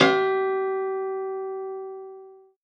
53w-pno03-G2.wav